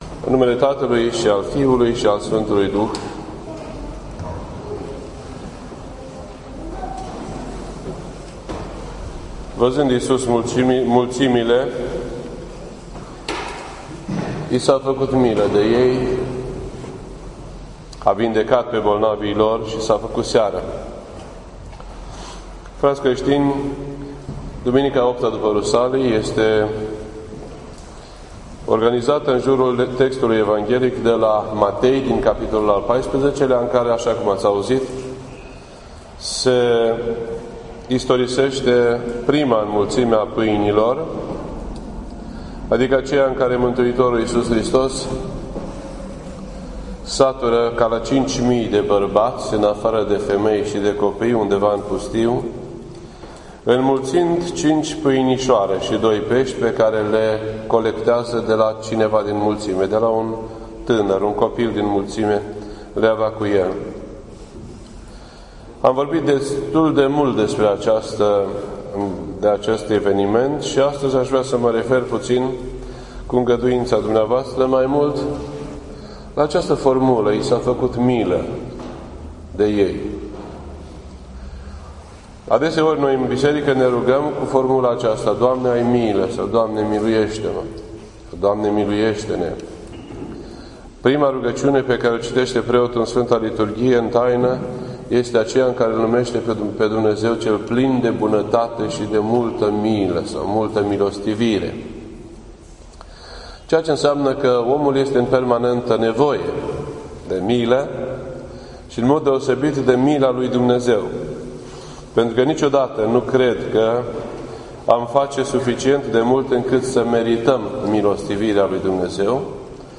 This entry was posted on Sunday, August 14th, 2016 at 10:35 AM and is filed under Predici ortodoxe in format audio.